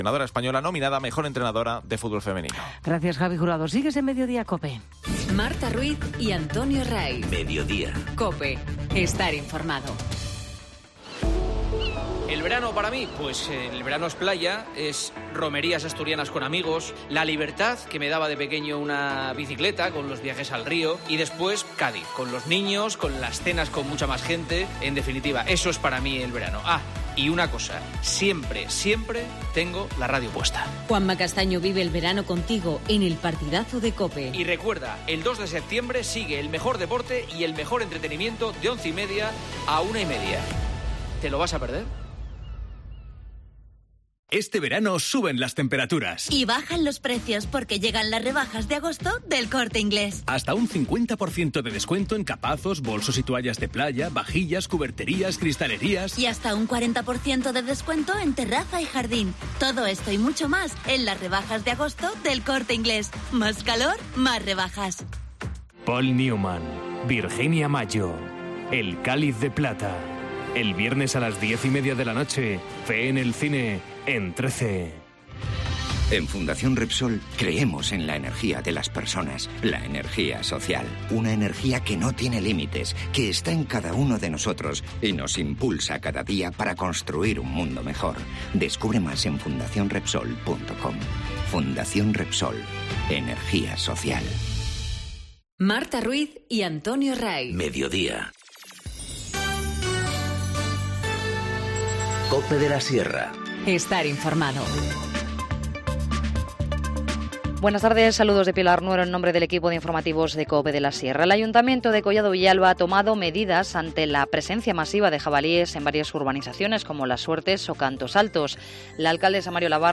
Informativo Mediodía 31 julio 14:20h